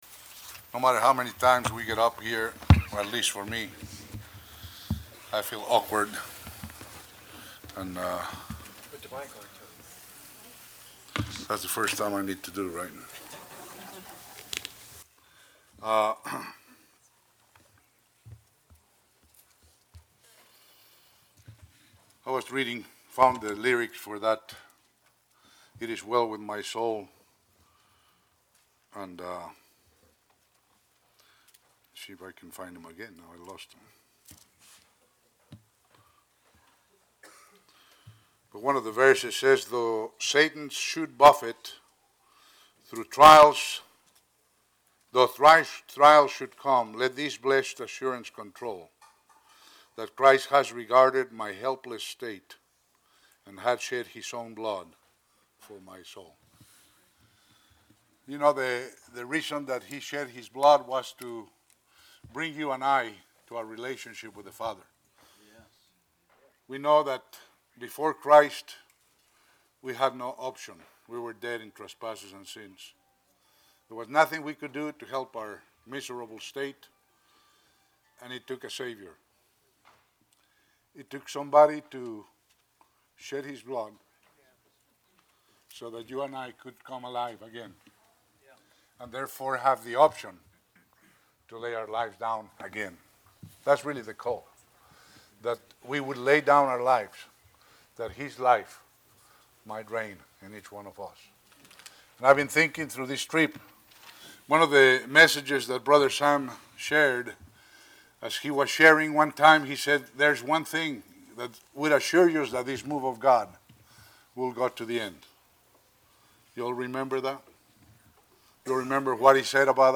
2018 Shepherds Christian Centre Convention , Teachings